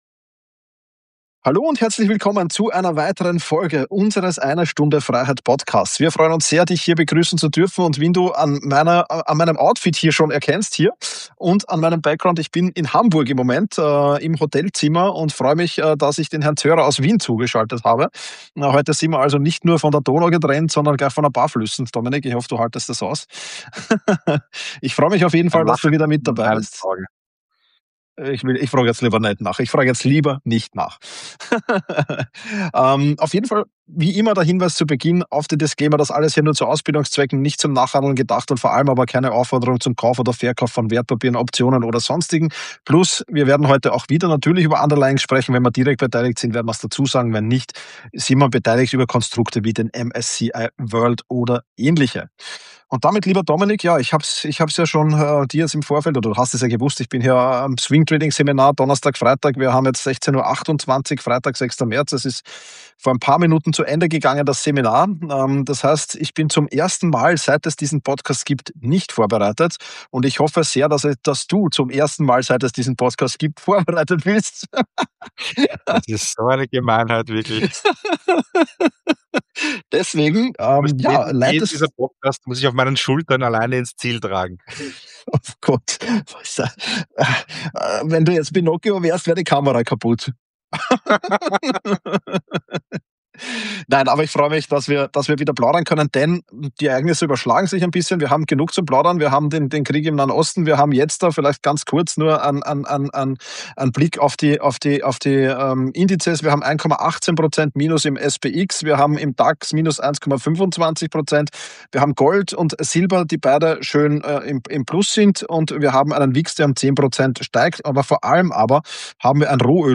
Frisch aus Hamburg zugeschaltet